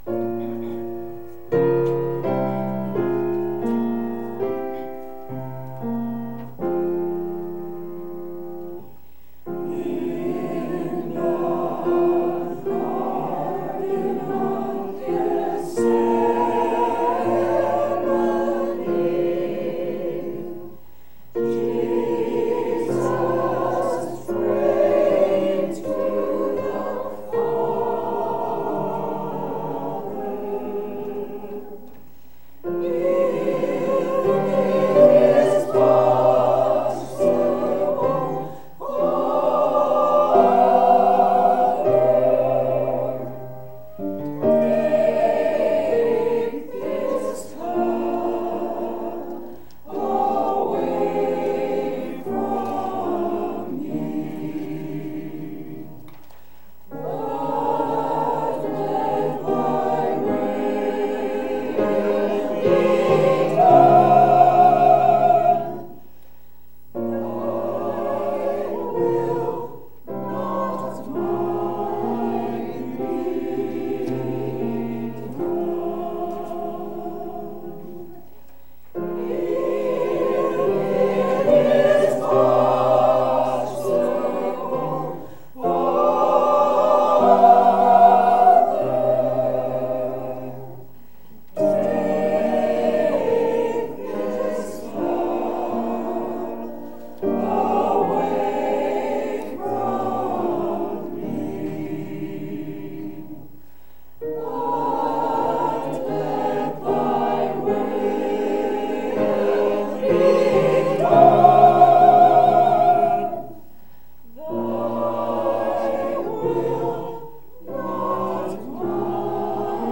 Church Choir
To hear the choir sing Gethsemane please click play below.
3-29-18-choir-gethsemane.mp3